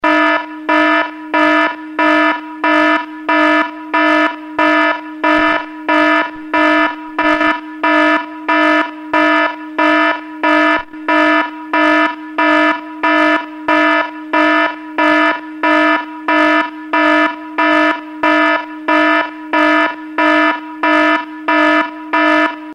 warn.mp3